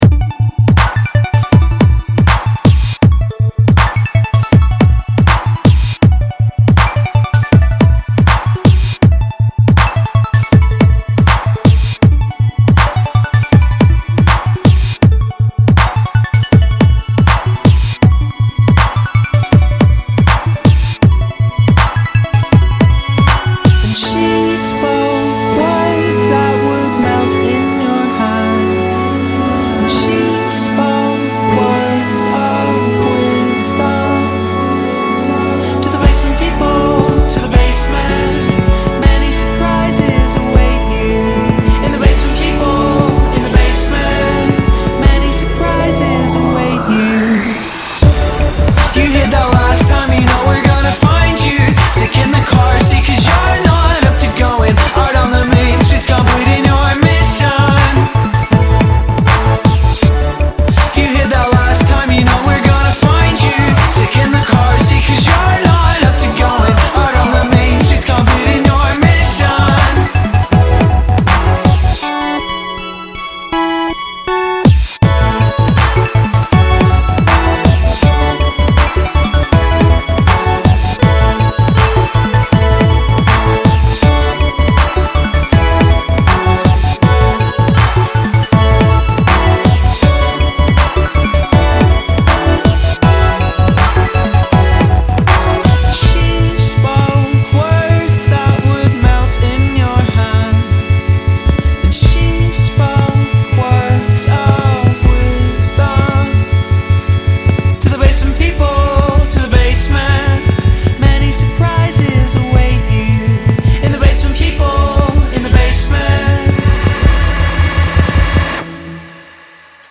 the remix was pretty chill.